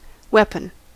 Ääntäminen
IPA : [ˈwɛ.pən]